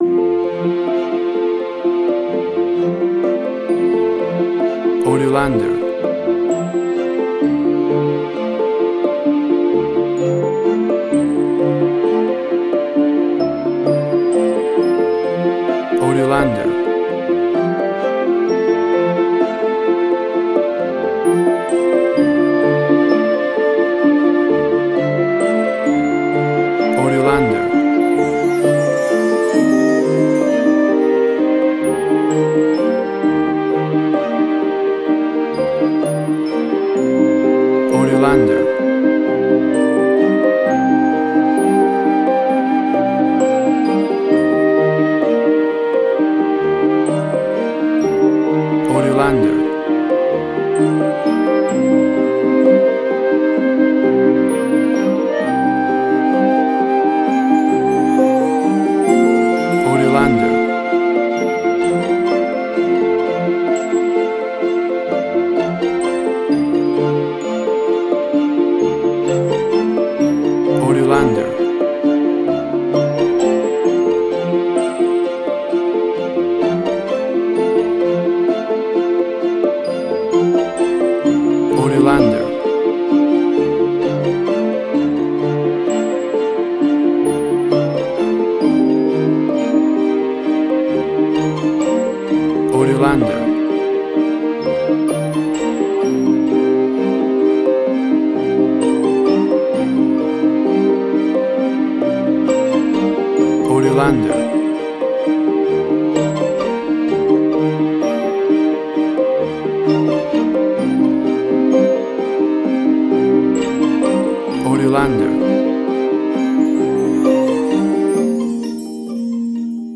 Soft ambient music, with flute, harp and strings
WAV Sample Rate: 24-Bit stereo, 48.0 kHz
Tempo (BPM): 65